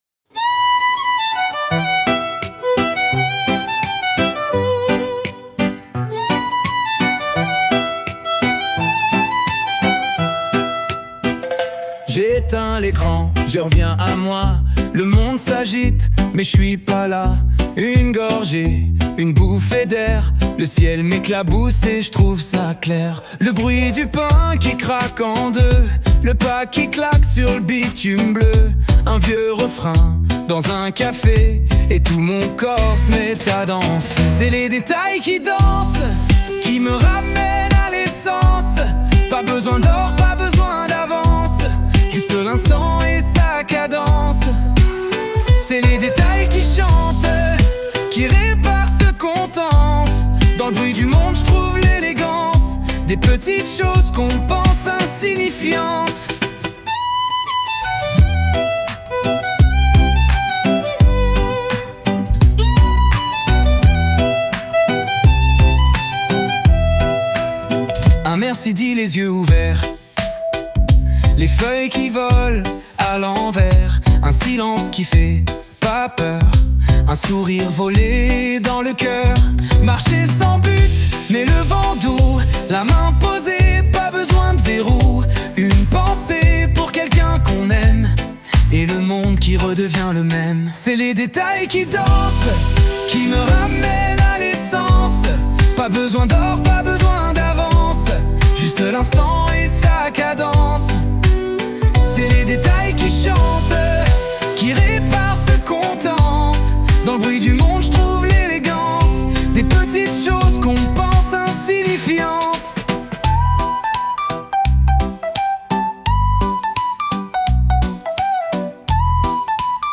Mettez-vous à l’aise, faites un peu d’espace, et bougez un peu au son de cette chanson :